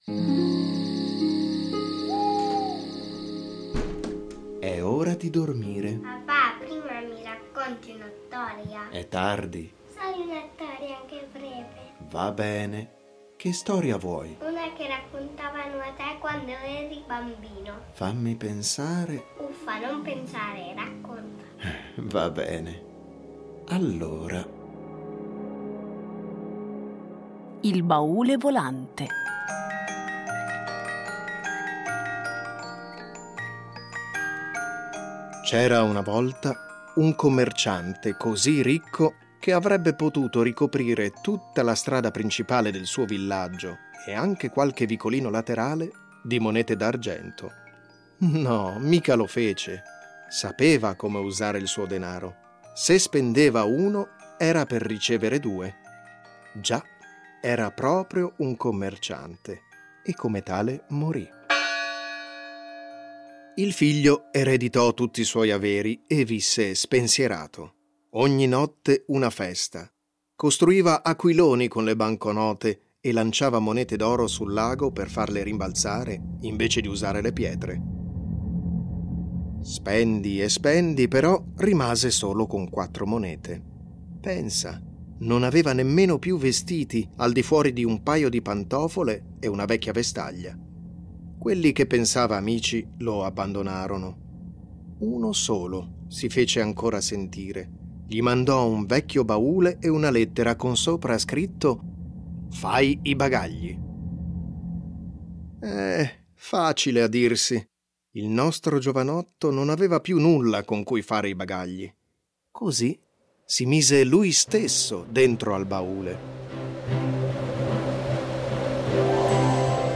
Fiabe
A partire dai testi originali un adattamento radiofonico per fare vivere ai bambini storie conosciute, ma un po’ dimenticate.